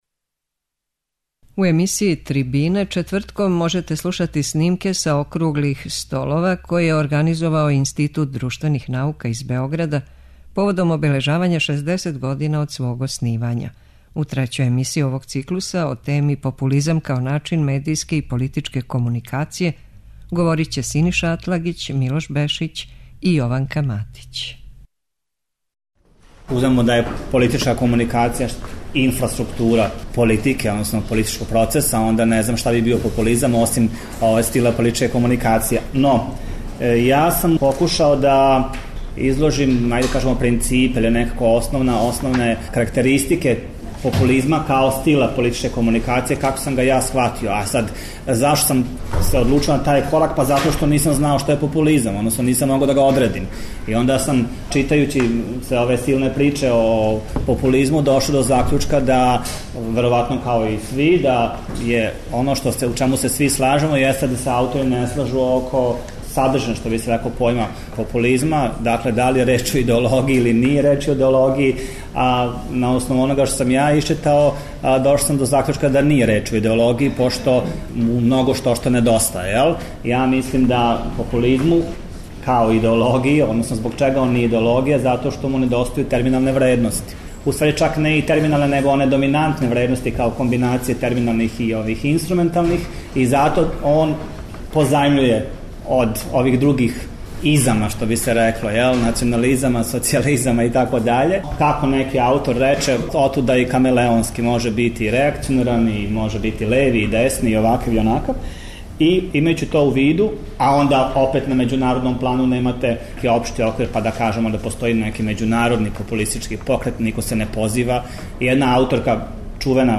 У емисији ТРИБИНЕ четвртком можете слушати снимке са округлих столова које током ове јесени организује Институт друштвених наука из Београда поводом обележавања 60 година од свог оснивања.